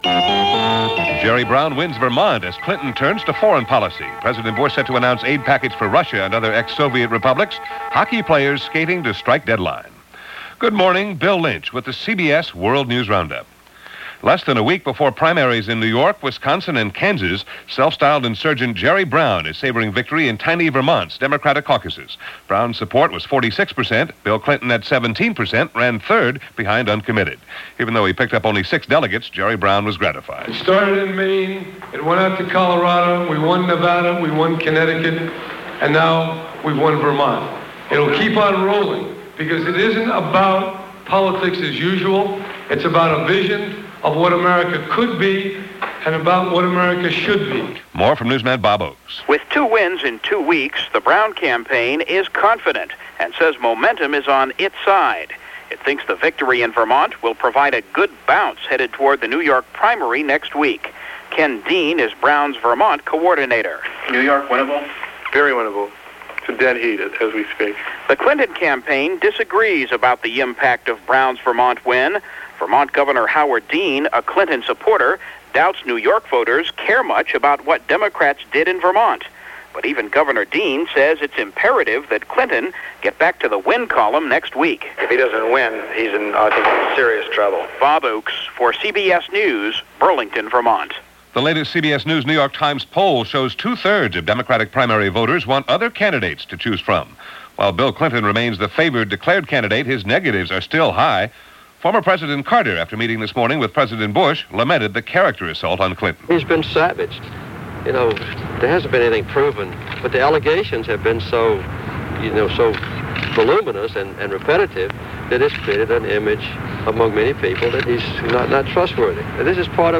And that’s just a small slice of what happened for this April 1, 1992 as presented by The CBS World News Roundup.